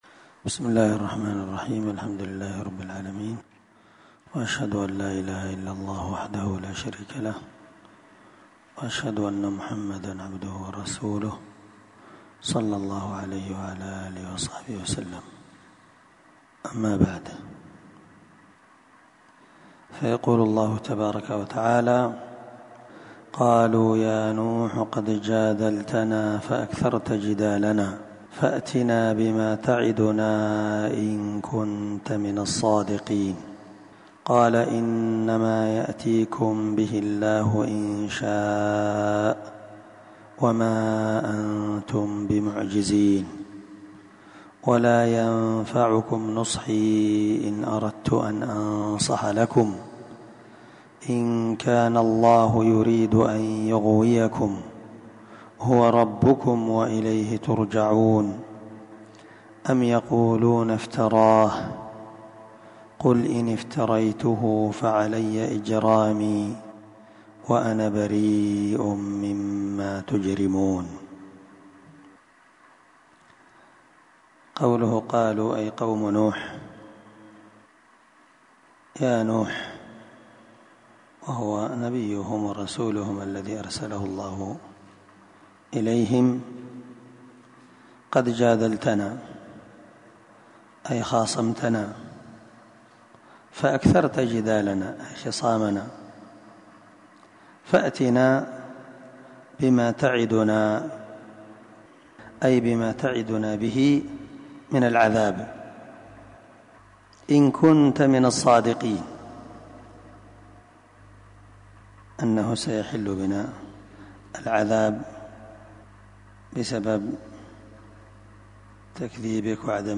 630الدرس 11تفسير آية ( 32- 35) من سورة هود من تفسير القرآن الكريم مع قراءة لتفسير السعدي
دار الحديث- المَحاوِلة- الصبيحة.